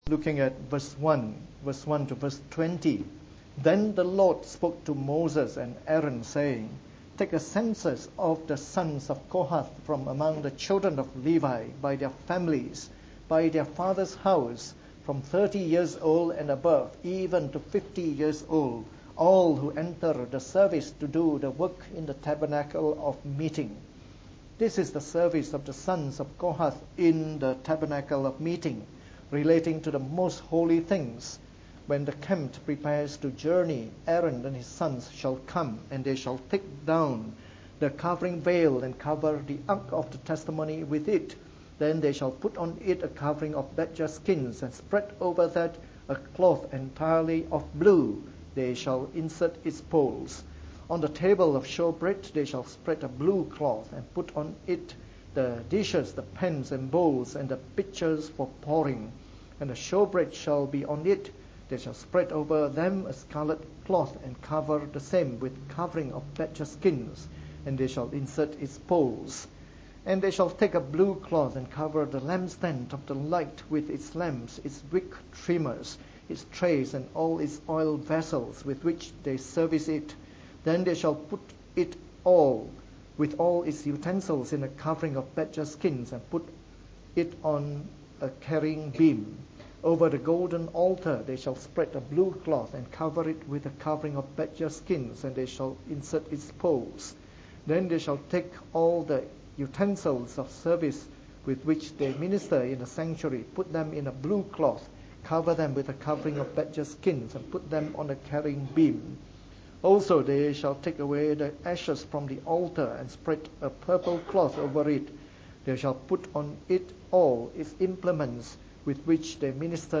From our new series on the “Book of Numbers” delivered in the Morning Service.